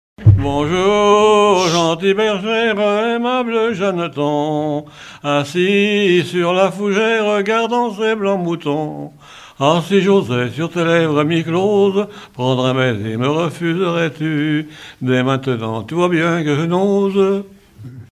Regroupement de chanteurs du canton
Pièce musicale inédite